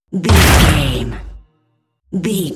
Dramatic hit hiss metal
Sound Effects
heavy
intense
dark
aggressive
hits